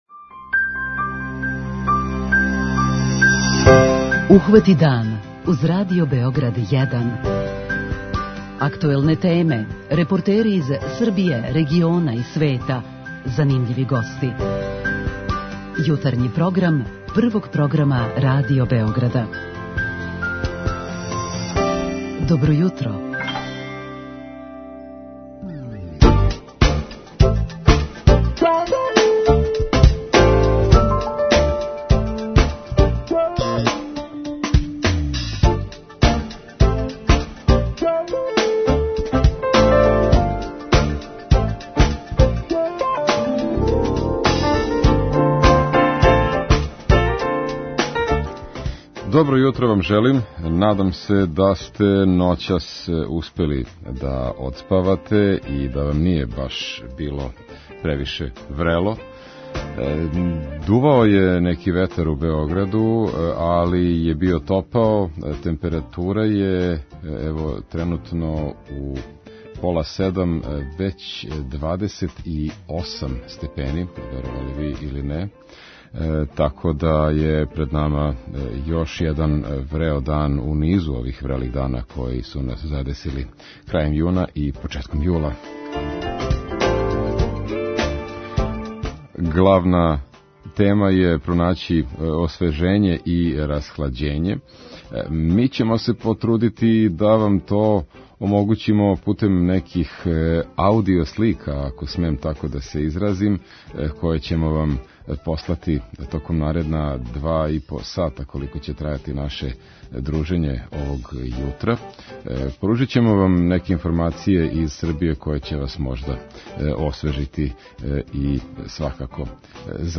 Јутарњи програм Радио Београда 1!
Чућемо и репортажу о сто година од почетка колонизације панонских крајева после Првог светског рата, као и причу о сарадњи немачке компаније МТУ и Ваздухопловне академије у Београду.